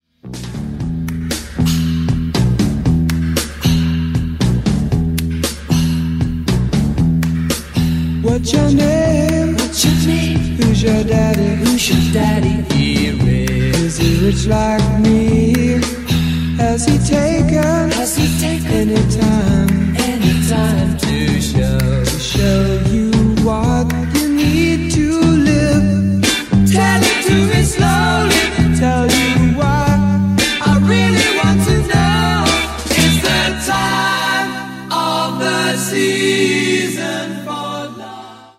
• Качество: 320, Stereo
гитара
мужской голос
спокойные
красивый женский голос
ретро
60-е